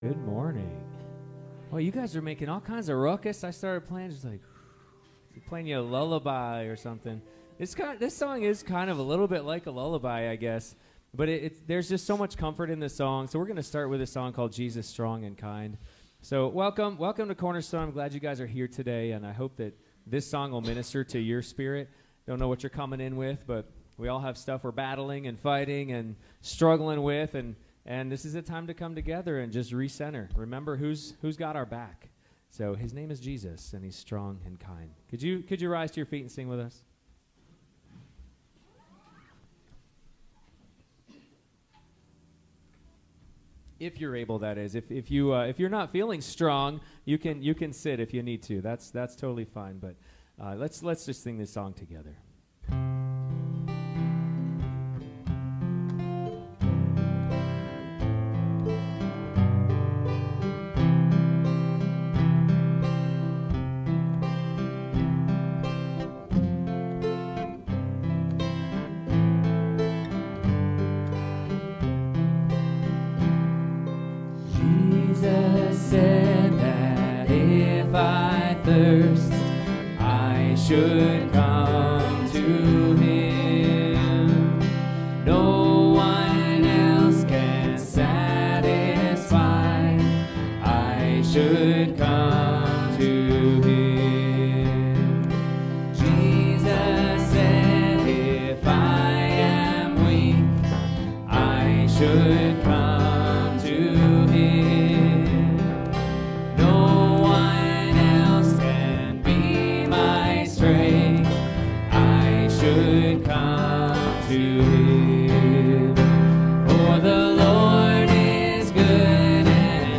Relationships Passage: Genesis 3 Service Type: Sunday Morning Youversion Event Bulletin PDF Download Files Bulletin « Adam & Eve’s 6 Bad Decisions in the Garden Where do I Start?